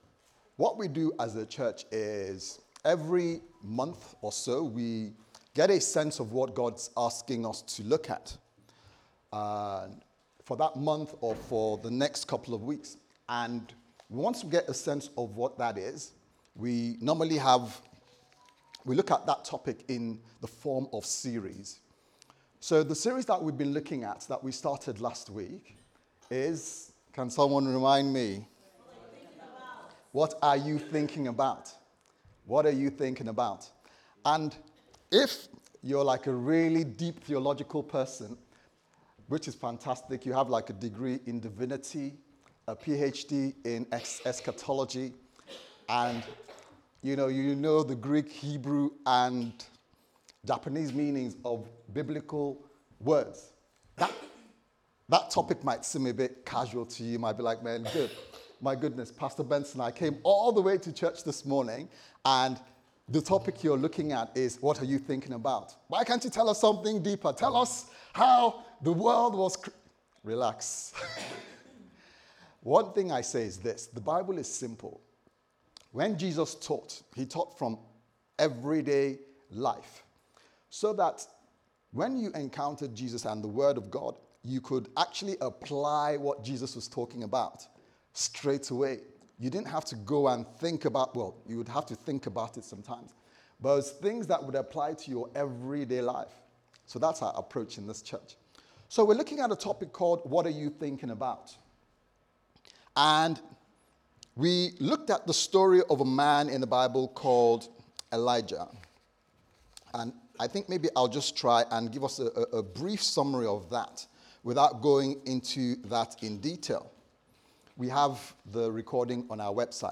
What Are You Thinking About Service Type: Sunday Service Sermon « What Are You Thinking About